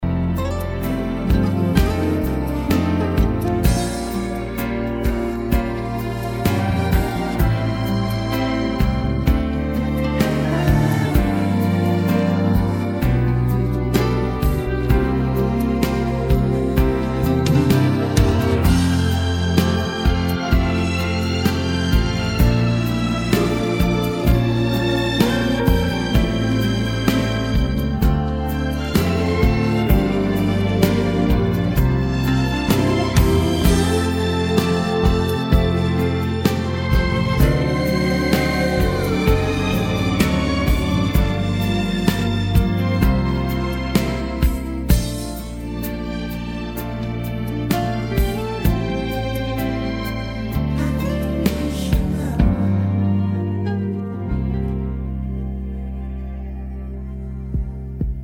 음정 원키 4:04
장르 가요 구분 Voice MR